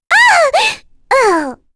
May-Vox_Dead.wav